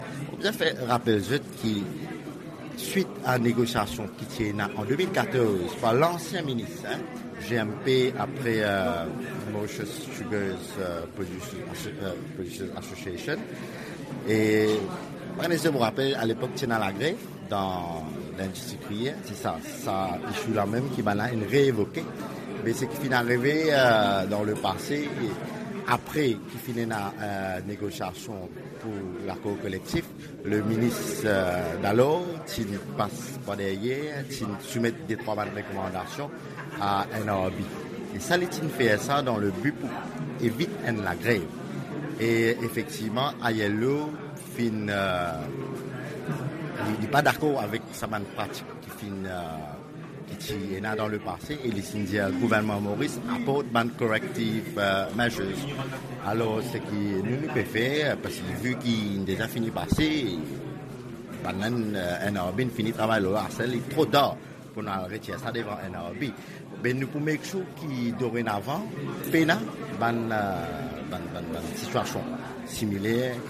Le Bureau international du travail a épinglé Maurice, qui a violé la Convention 98 sur la négociation collective. Réaction du ministre du Travail, Soodesh Callichurn, le jeudi 28 juillet.